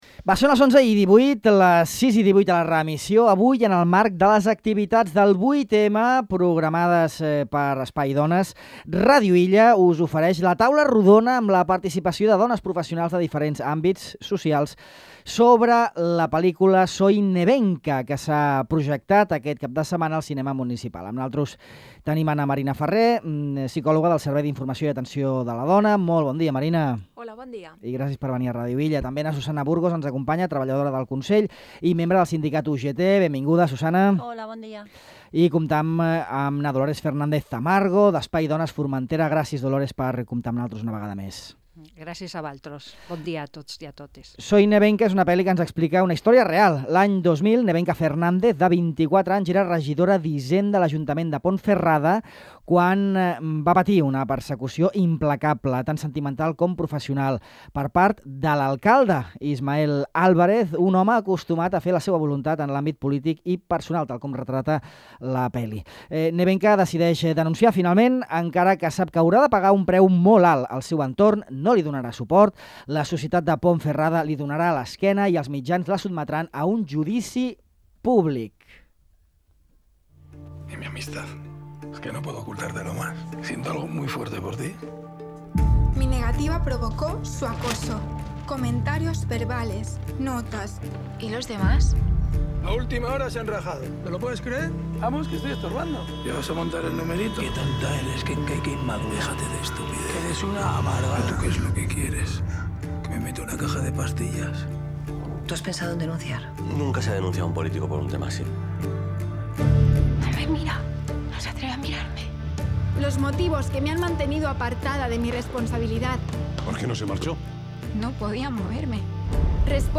Aquesta taula rodona, coordinada per Espai Dones, forma part del cartell del 8M de 2025 a Formentera.